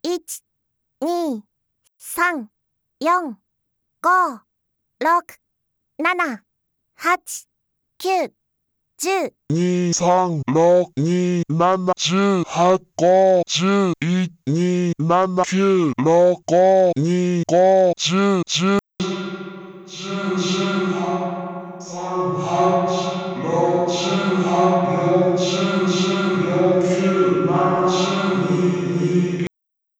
音質もローファイ方面へメチャ変わります。
3回繰り返し最初はそのまま、次にグラニュラー加工、更に内蔵エフェクトを加えたものです。
ectocoreはキャベツの千切りスライサーです。
個々の音はectocoreの方がハッキリ聴こえます。